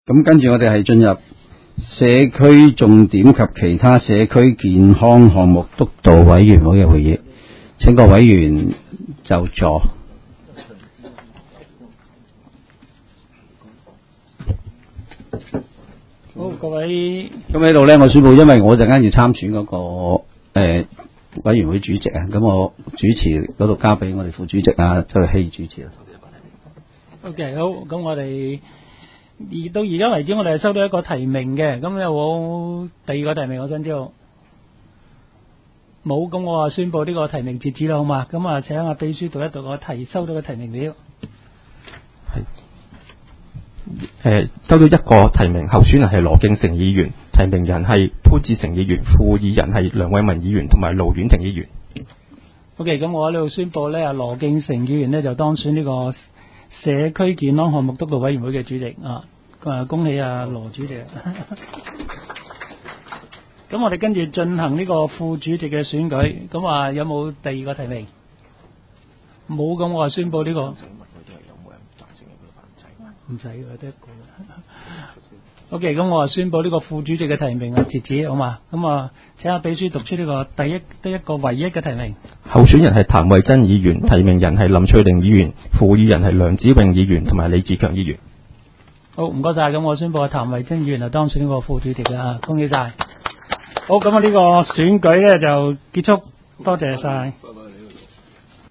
委员会会议的录音记录
地点: 香港葵涌兴芳路166-174号 葵兴政府合署10楼 葵青民政事务处会议室